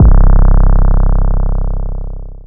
DEEDOTWILL 808 73.wav